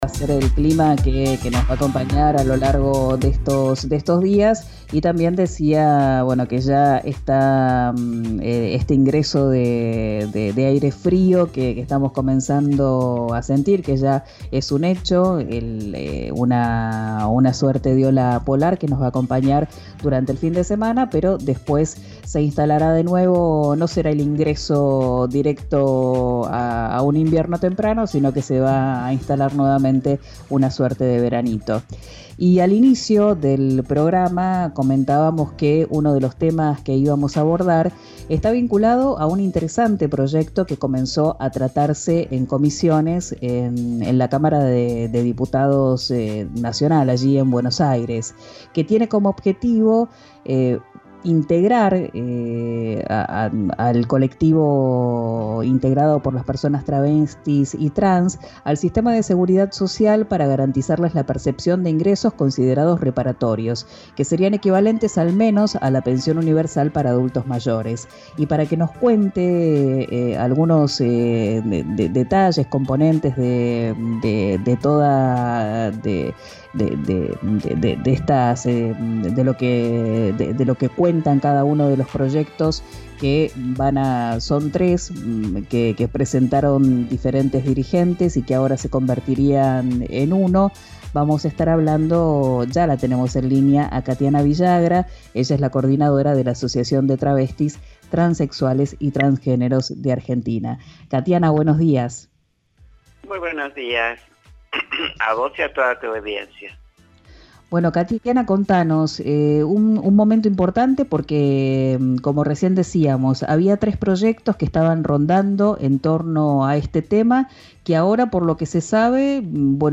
Esta semana se comenzó a tratar en la Cámara de Diputados. Escuchá la entrevista